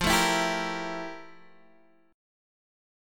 FM11 Chord
Listen to FM11 strummed